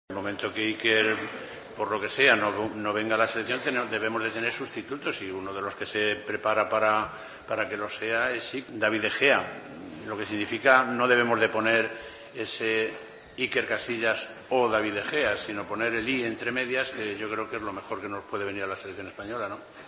Del Bosque ha realizado estas declaraciones durante la presentación en Alicante del partido amistoso que enfrentará a las selecciones de España e Inglaterra el próximo 13 de noviembre: "En el momento en el que Iker por lo que sea no venga a la selección debemos tener sustituto y uno de los que se prepara para serlo es David de Gea. No debemos de poner Iker Casillas o David de Gea sino poner el 'y' entre medias. Es lo que mejor puede venir a la selección"